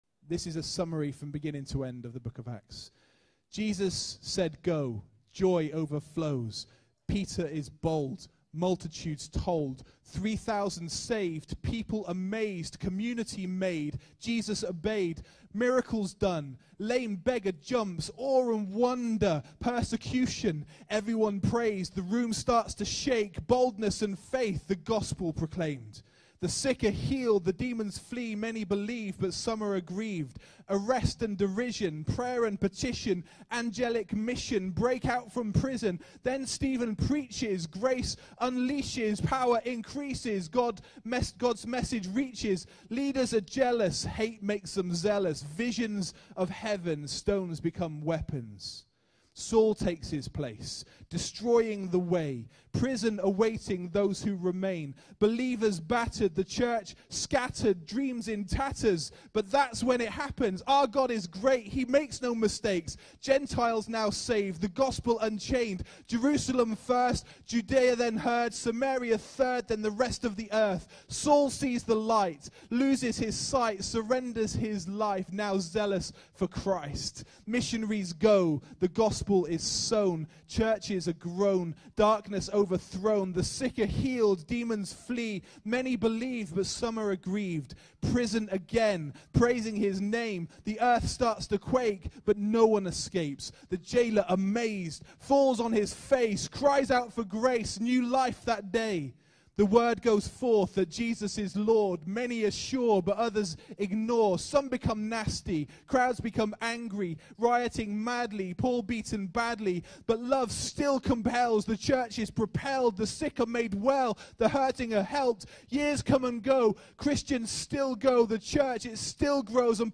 Acts-Rap.mp3